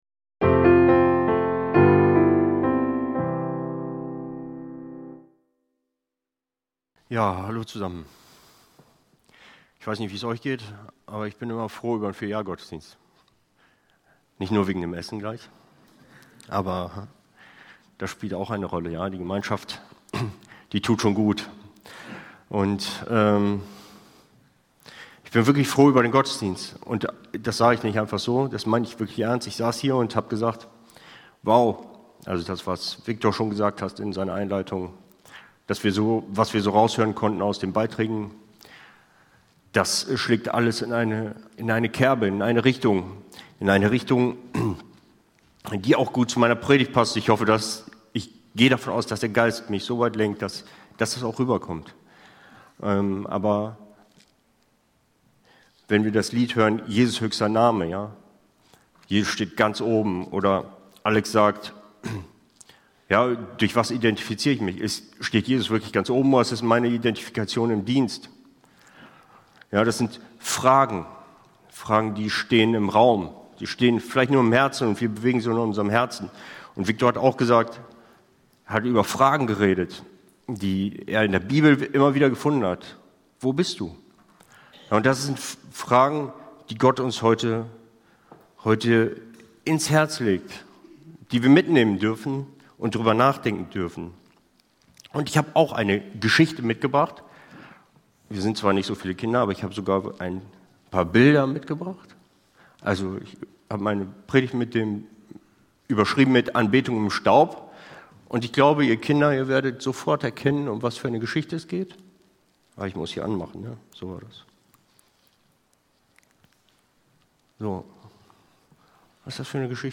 Höre inspirierende Predigten und lerne Jesus besser kennen.